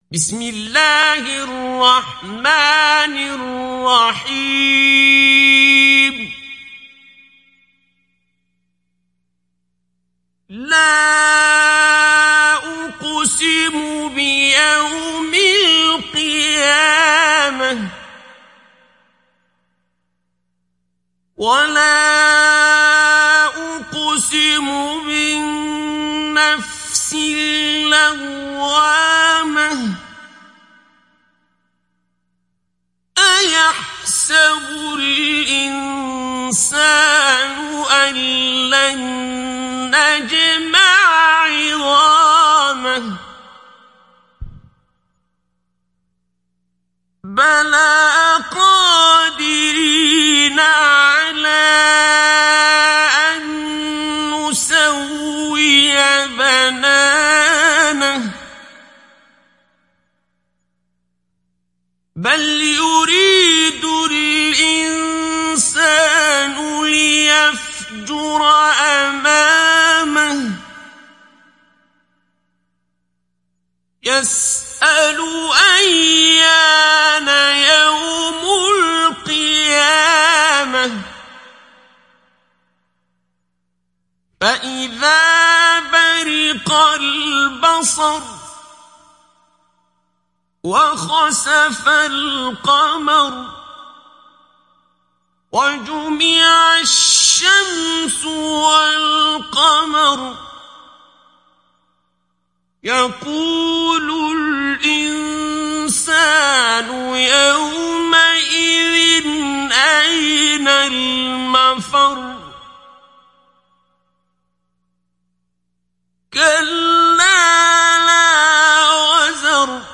Surat Al Qiyamah Download mp3 Abdul Basit Abd Alsamad Mujawwad Riwayat Hafs dari Asim, Download Quran dan mendengarkan mp3 tautan langsung penuh
Download Surat Al Qiyamah Abdul Basit Abd Alsamad Mujawwad